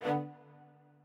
strings6_24.ogg